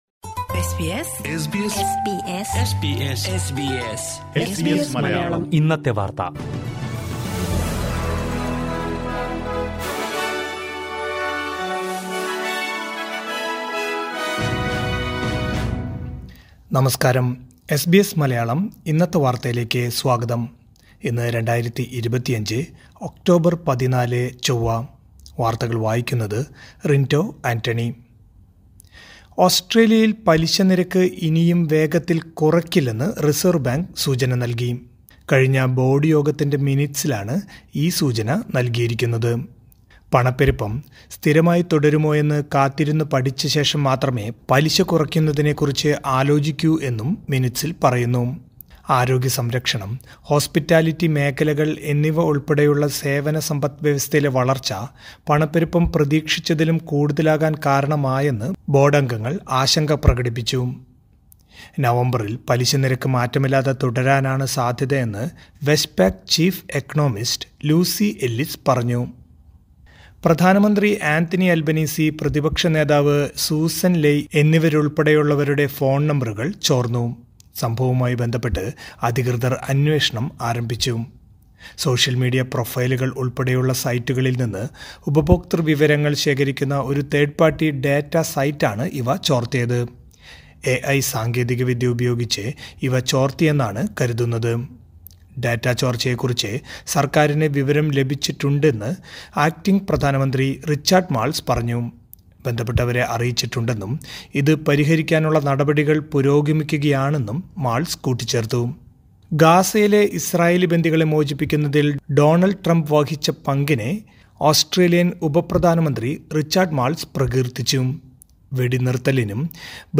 2025 ഒക്ടോബർ 14ലെ ഓസ്ട്രേലിയയിലെ ഏറ്റവും പ്രധാന വാർത്തകൾ കേൾക്കാം...